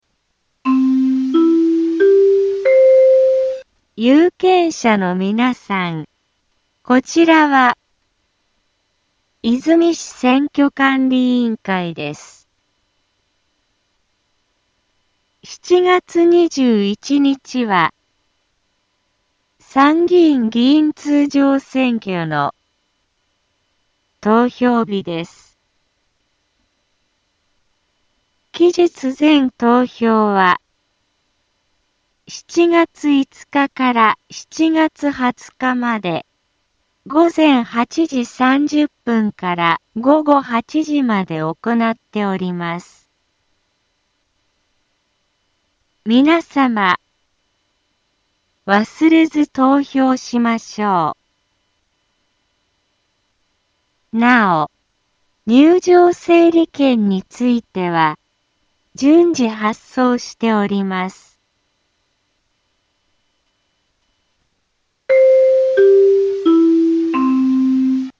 Back Home 災害情報 音声放送 再生 災害情報 カテゴリ：通常放送 住所：大阪府和泉市府中町２丁目７−５ インフォメーション：有権者のみなさん こちらは、和泉市選挙管理委員会です。 7月21日は、参議院議員通常選挙の、投票日です。